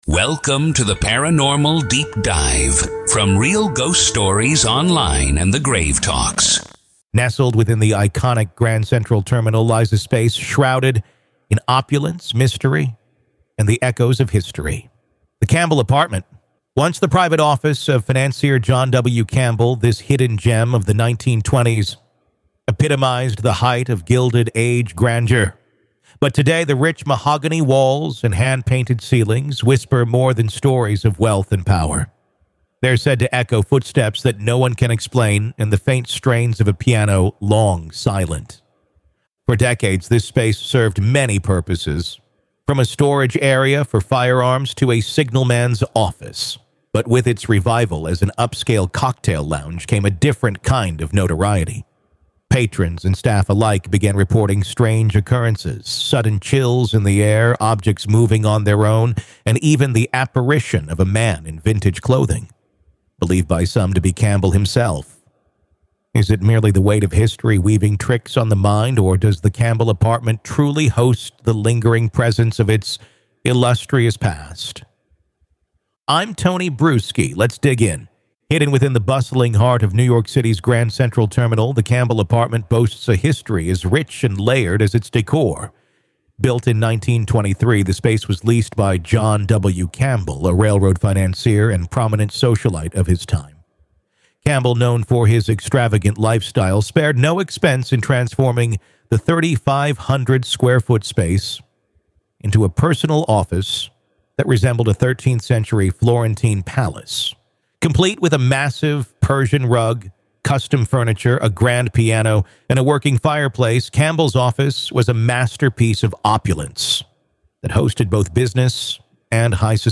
We'll also examine the numerous reports of paranormal activity that have emerged since its reopening, including sightings of a mysterious figure in 1920s attire and other unexplained phenomena. Through interviews with staff, patrons, and paranormal investigators, we'll seek to uncover whether these accounts are mere remnants of the past or if there's something more otherworldly at play.